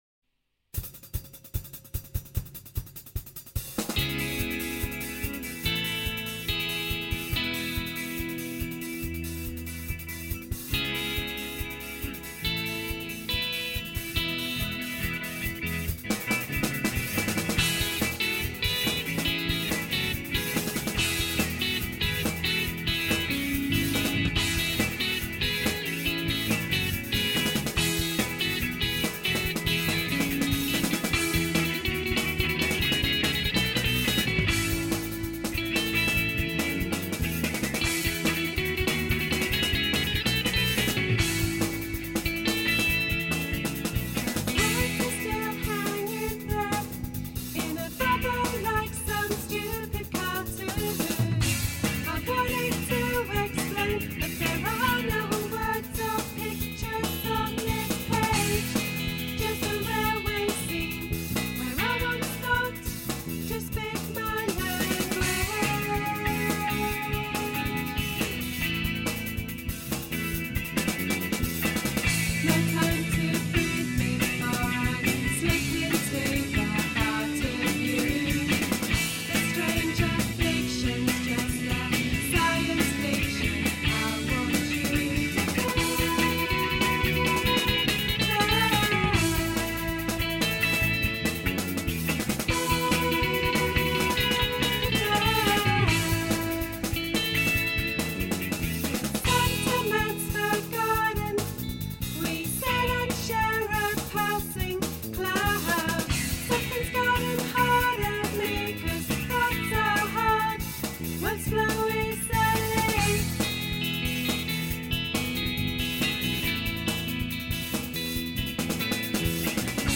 Indiepop!
keyboards
vocals
lead guitar
bass
drums
recorded and mixed at Cornerstone Studios in London